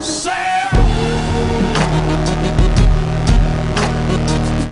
Play, download and share AOL SALE BUTTON original sound button!!!!
sale-button.mp3